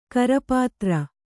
♪ karapātra